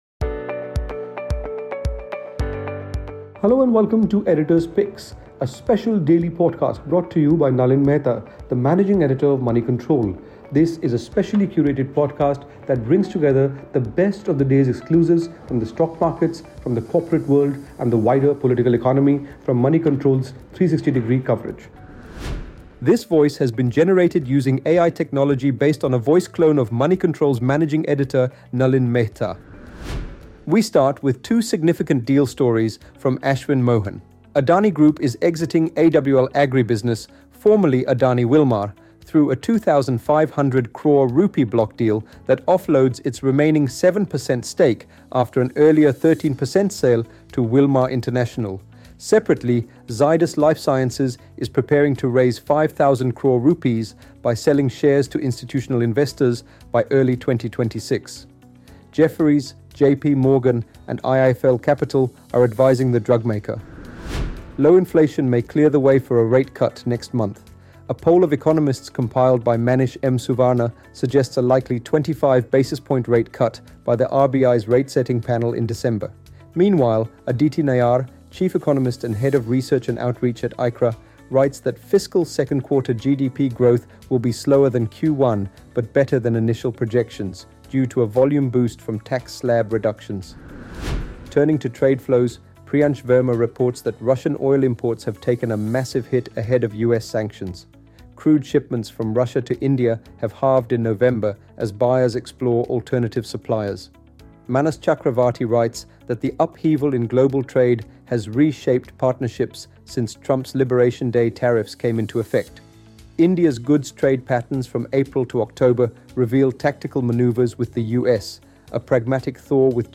Also find an interview with Arvind Panagariya where he reveals why he is upbeat about India’s economic resilience.